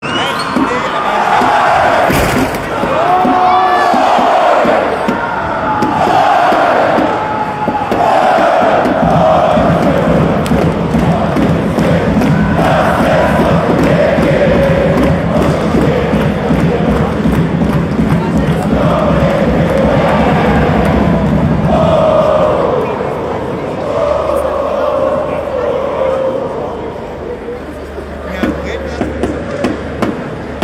Wie bei nahezu jeder Teamsportart dürfen auch beim Eishockey die Fangesänge nicht fehlen. Wir haben euch die beliebtesten aus dieser Saison zusammengestellt.